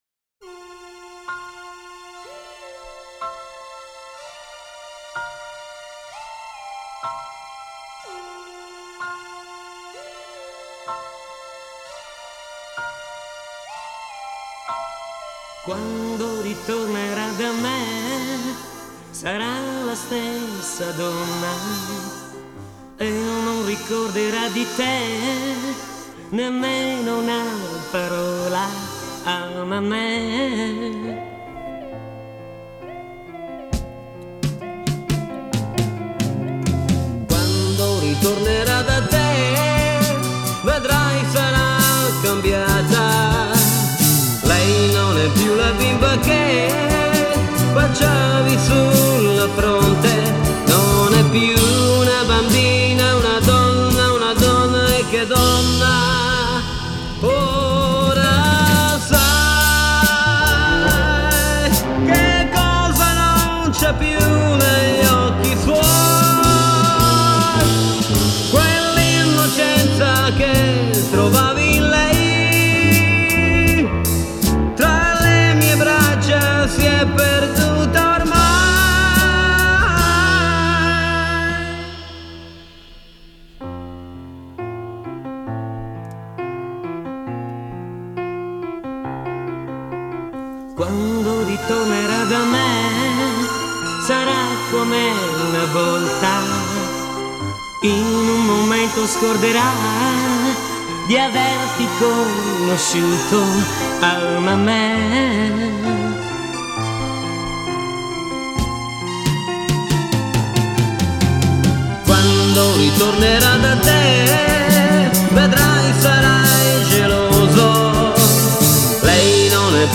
Итальянская эстрада
vocals, keyboard
guitar
drums
bass